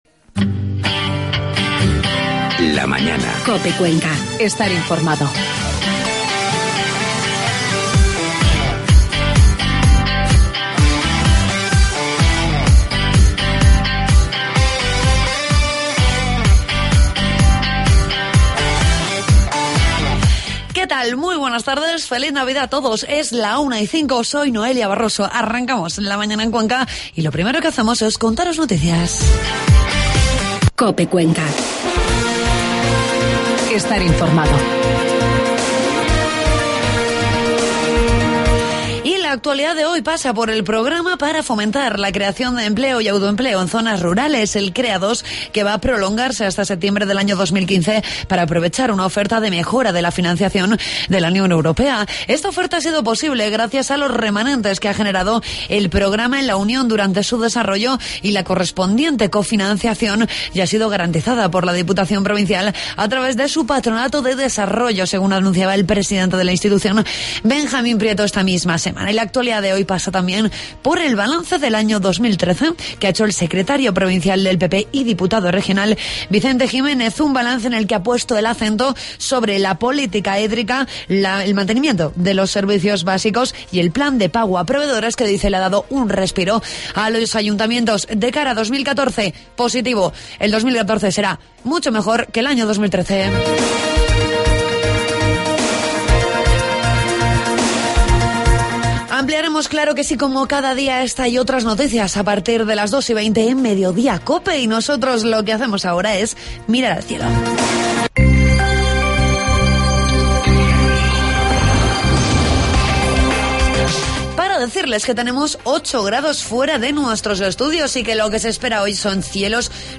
Entrevistamos
Además, escuchamos un relato Navideño